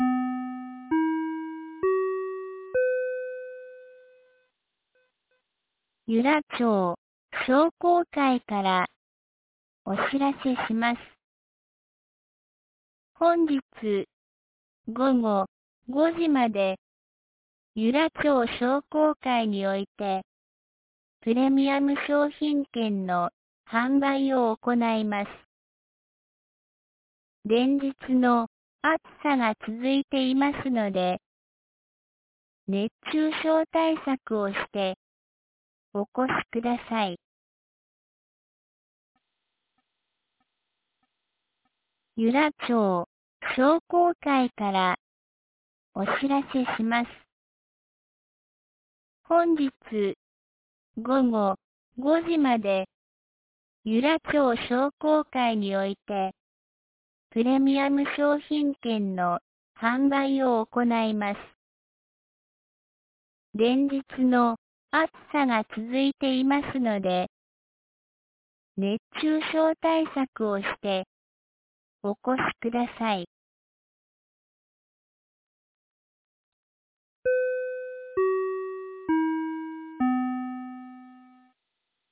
2025年09月01日 12時21分に、由良町から全地区へ放送がありました。